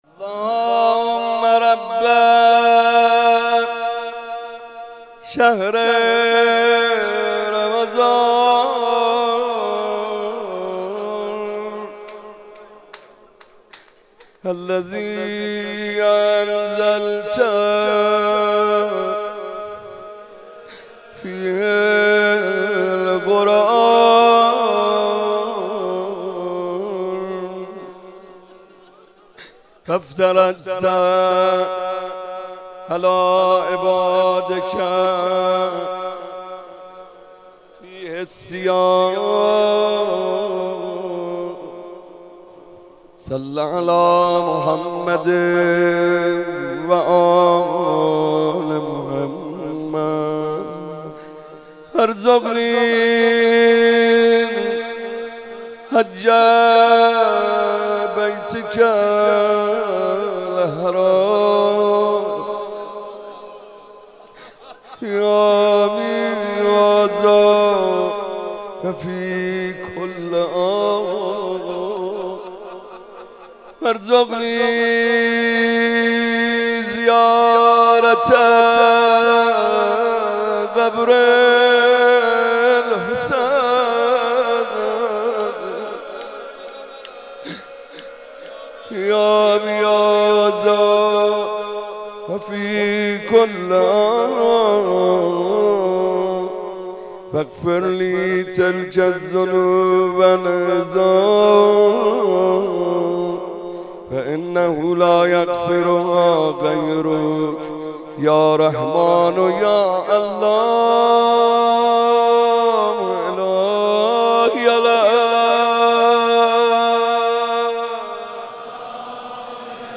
مناسبت : شب بیست و سوم رمضان - شب قدر سوم
مداح : محمدرضا طاهری قالب : مناجات